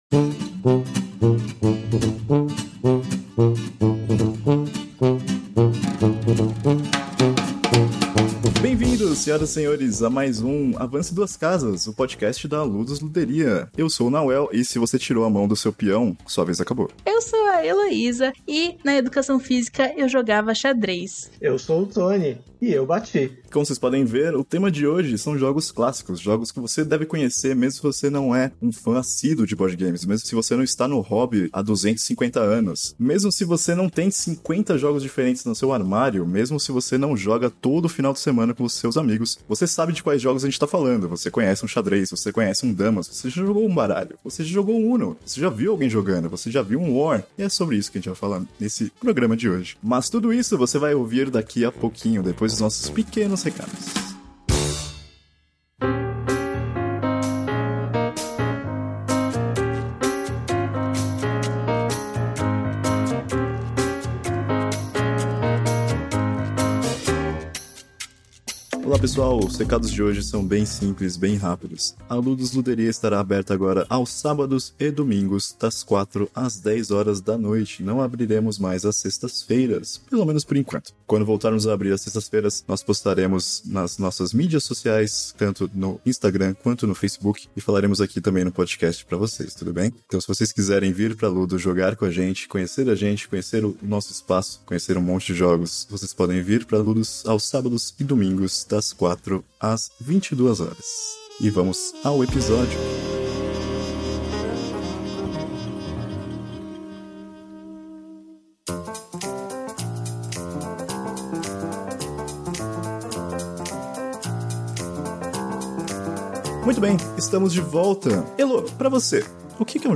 Venha ouvir essa conversa super maneira no Avance Duas Casas!